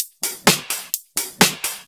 Index of /VEE/VEE2 Loops 128BPM
VEE2 Electro Loop 227.wav